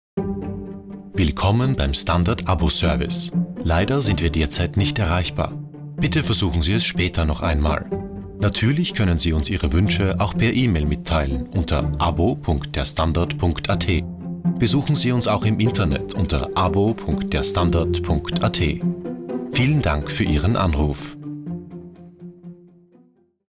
Professioneller Sprecher (Deutsch) aus Wien.
Sprechprobe: Sonstiges (Muttersprache):